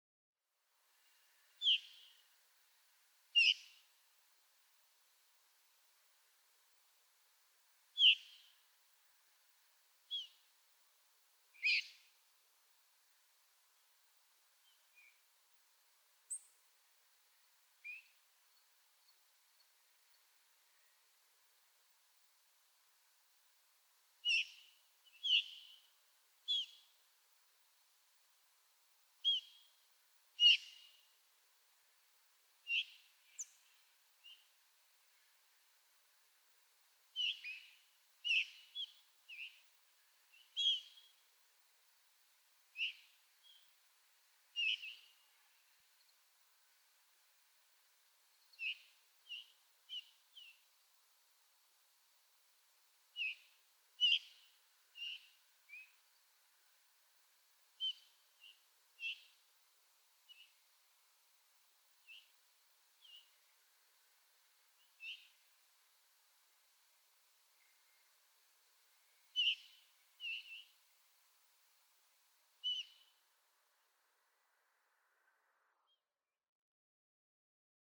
Veery
"Veeeer" calls by night migrants.
Hadley, Massachusetts.
013_Veery.mp3